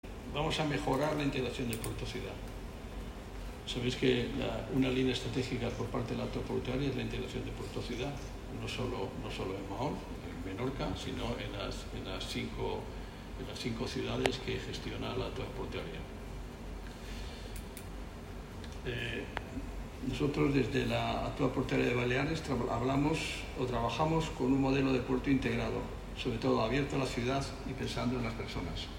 El president de l'APB, Javier Sanz, ha destacat que a més de la inversió, el que destaca d'aquests projectes és el procés participatiu en el que s'enmarca la línia estratègica de millora del port-ciutat,